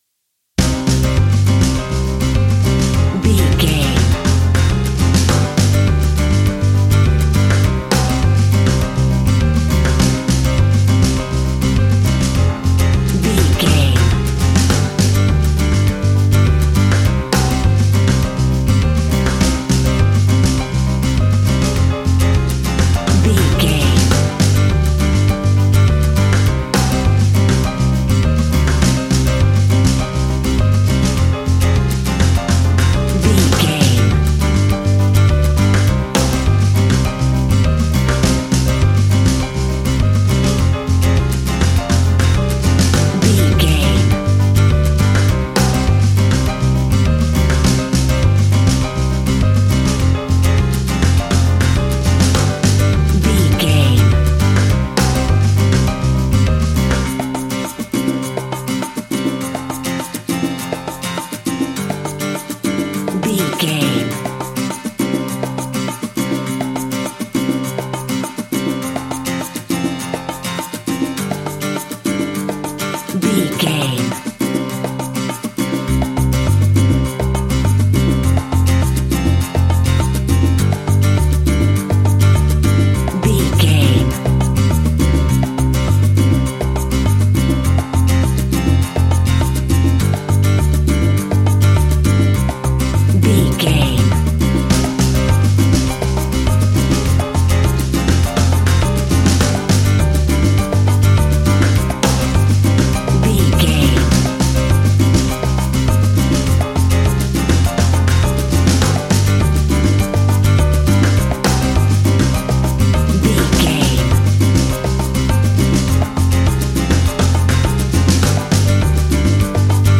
An exotic and colorful piece of Espanic and Latin music.
Ionian/Major
D
flamenco
romantic
maracas
percussion spanish guitar